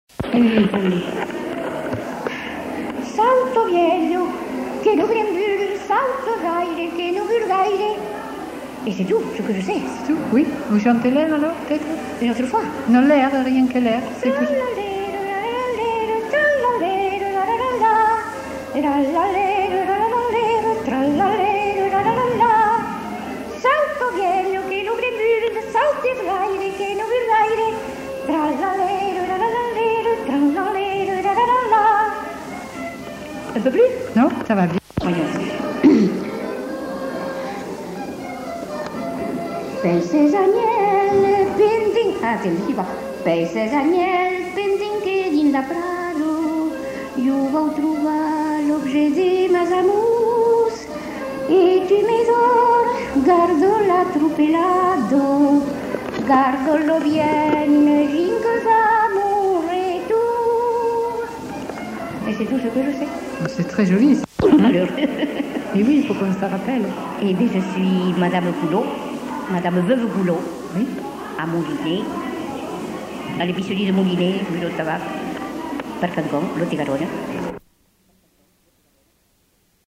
enquêtes sonores